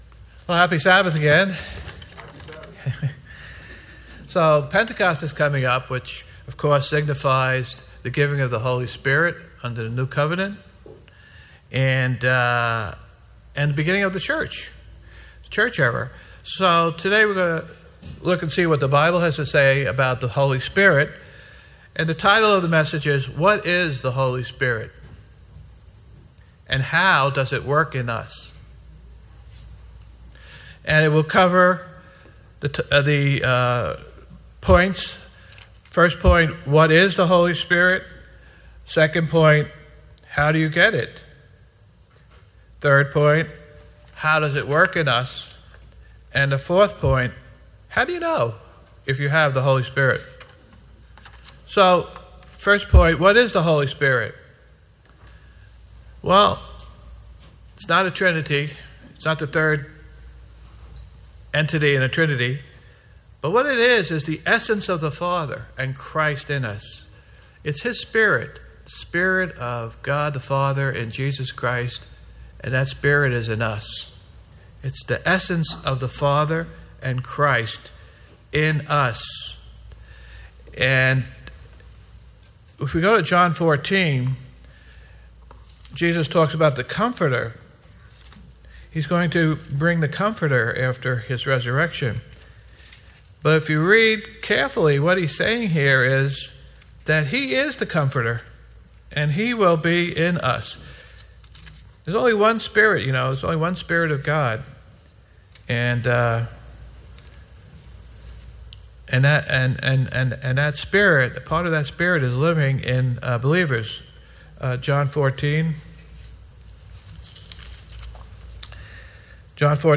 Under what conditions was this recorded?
Given in New York City, NY